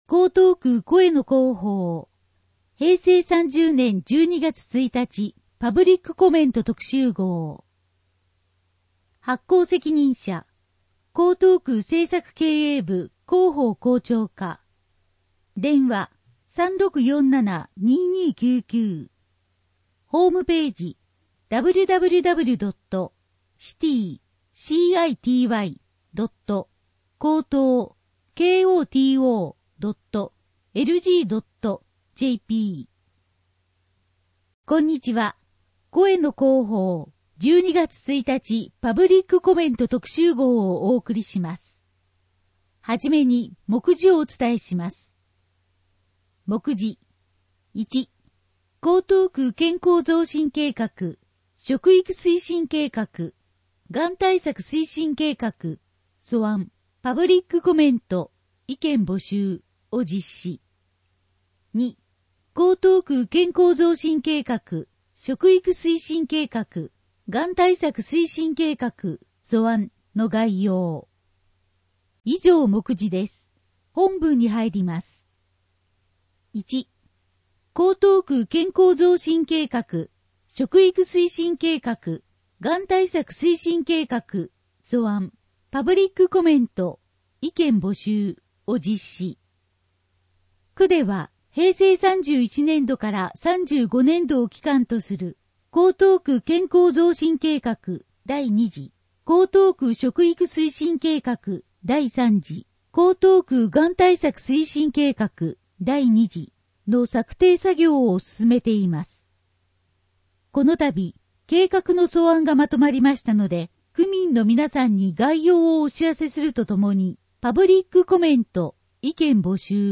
声の広報 平成30年12月1日号（1-2面）